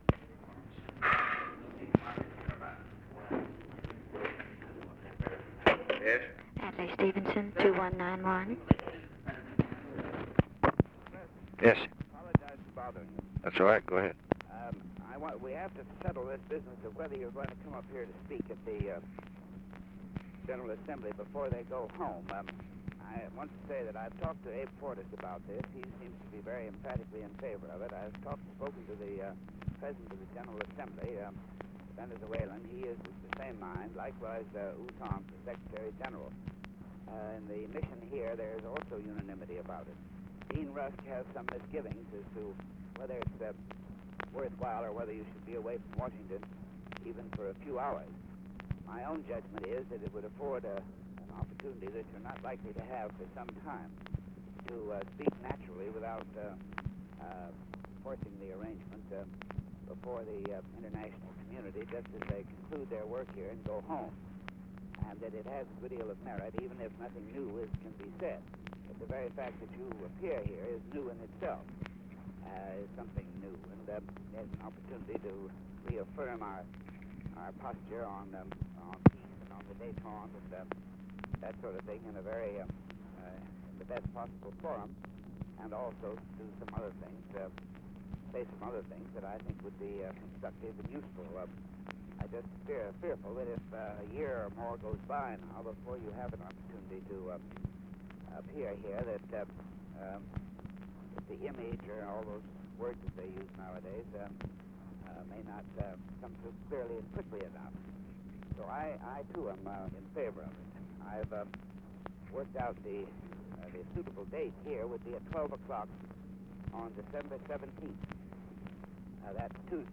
Conversation with ADLAI STEVENSON, December 6, 1963
Secret White House Tapes